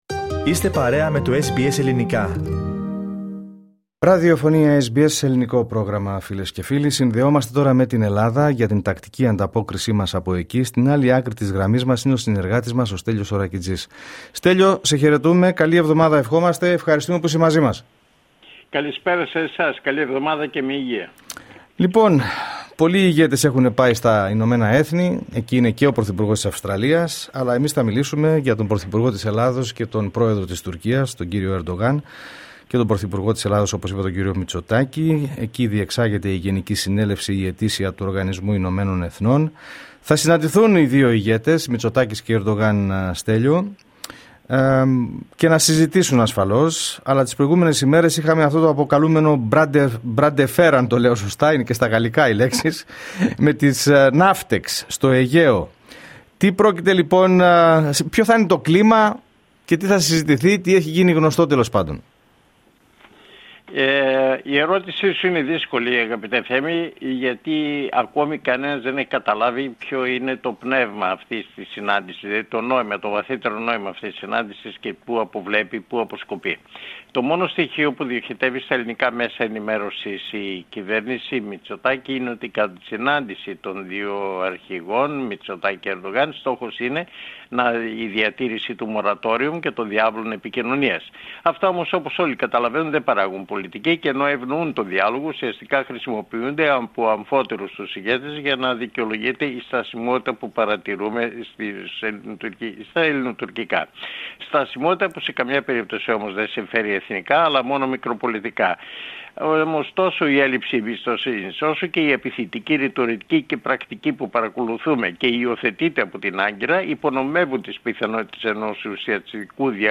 Ανταπόκριση-Ελλάδα: Τα συμπεράσματα από την παρουσία του Κυριάκου Μητσοτάκη στον ΟΗΕ